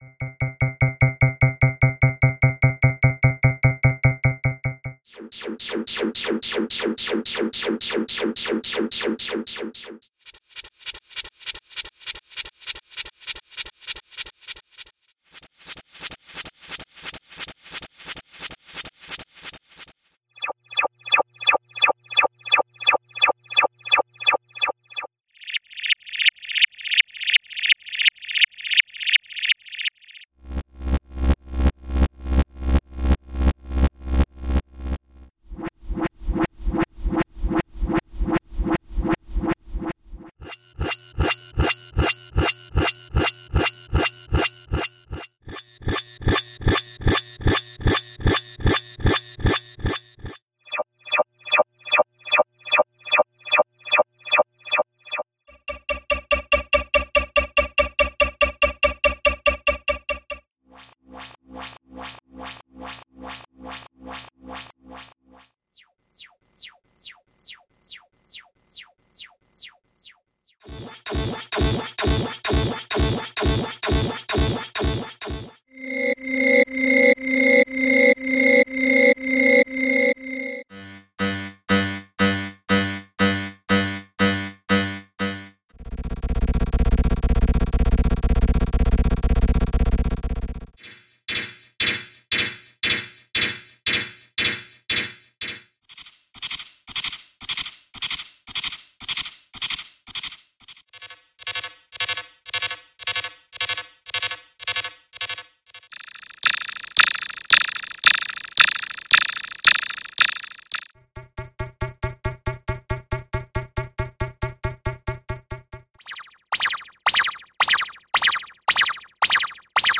Sound Effects - SIGNALS - V9 - p1